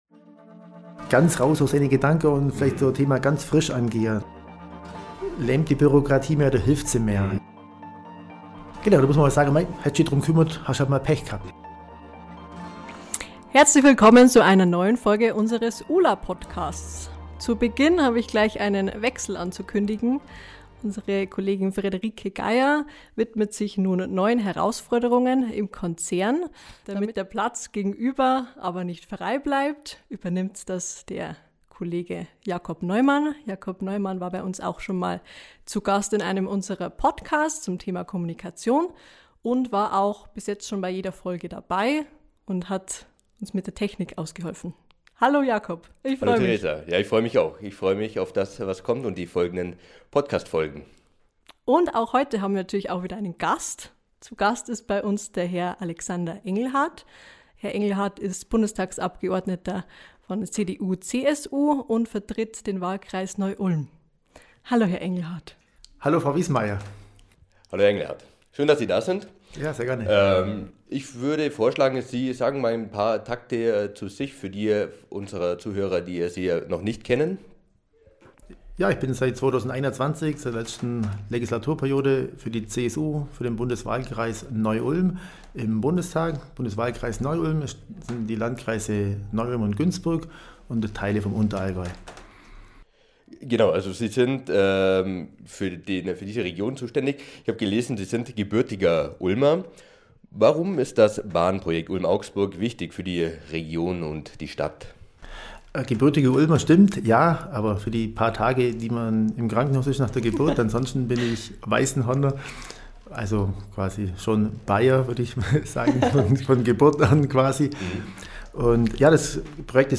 #8 Im Gespräch mit Alexander Engelhard ~ Bahnprojekt Ulm-Augsburg: Der Podcast